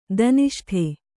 ♪ daniṣṭhe